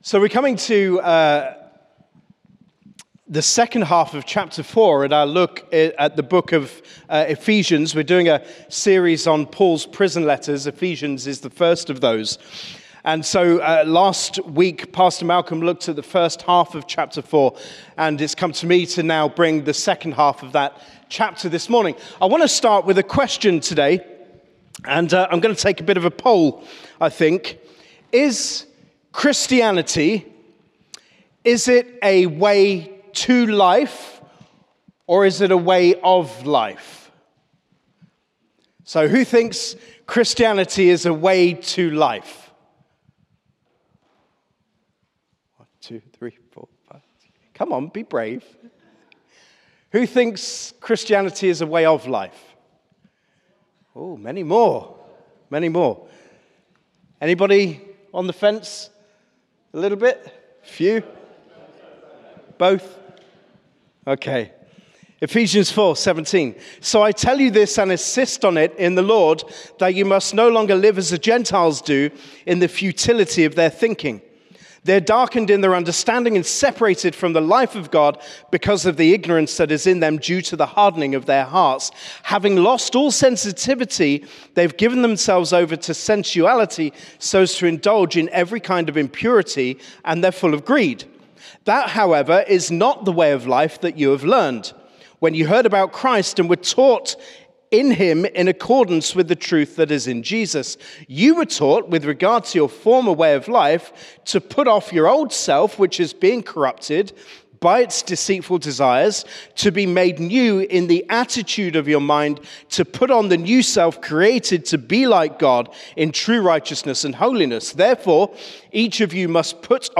Sermon - Ephesians 4:17-32